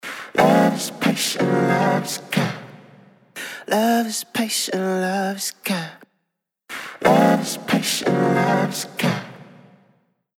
Octavox | Vocals | Preset: Modal 1
Octavox-Eventide-Male-Vocal-Modal-1.mp3